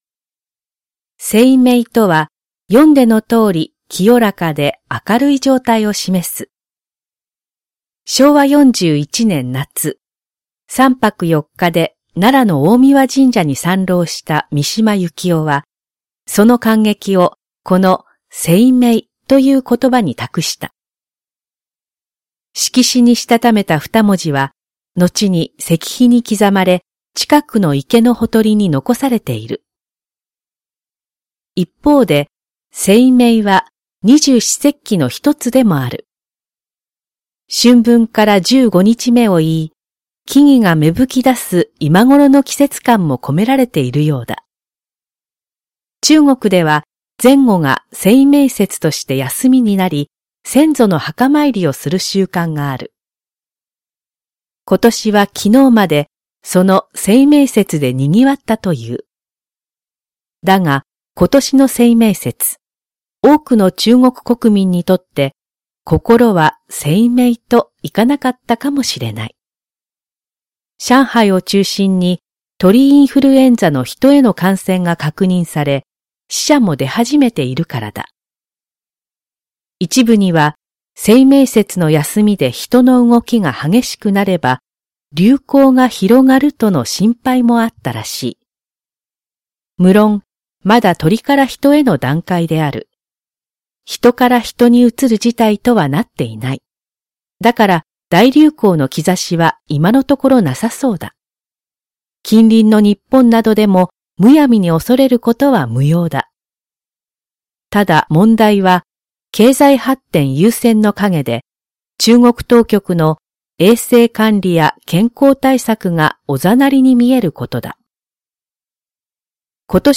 全国240名の登録がある局アナ経験者がお届けする番組「JKNTV」
産経新聞1面のコラム「産経抄」を、局アナnetメンバーが毎日音読してお届けします。